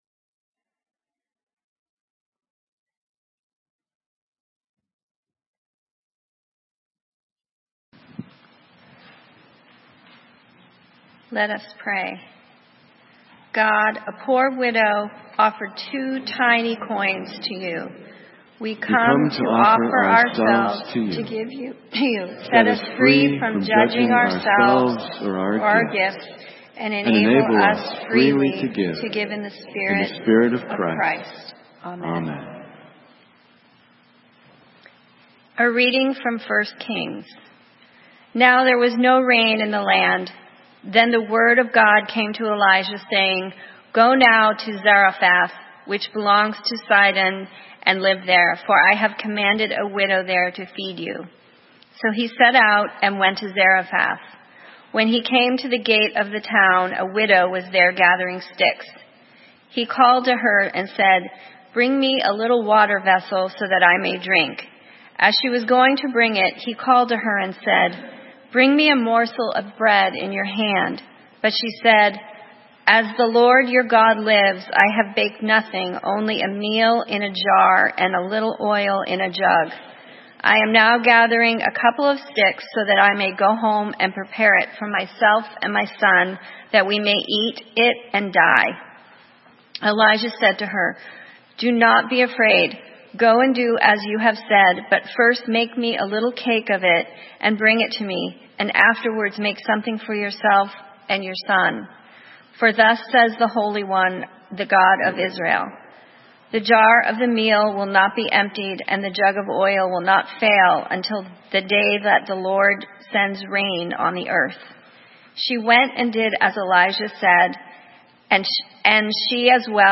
Sermon:Your two cents' worth - St. Matthews United Methodist Church